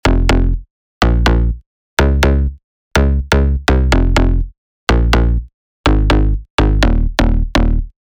Inside, you’ll find all of the essentials from punchy basses, powerful drones, lush pads, and much more.